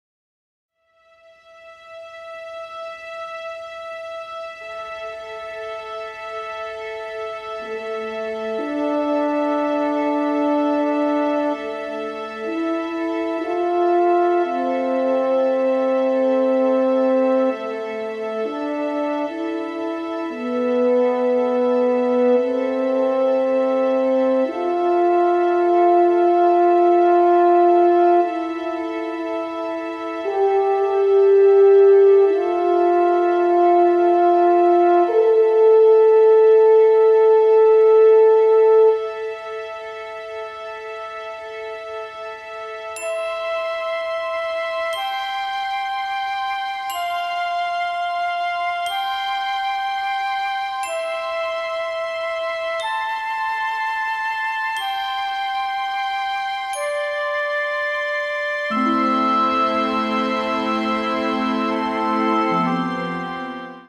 entirely composed using electronic elements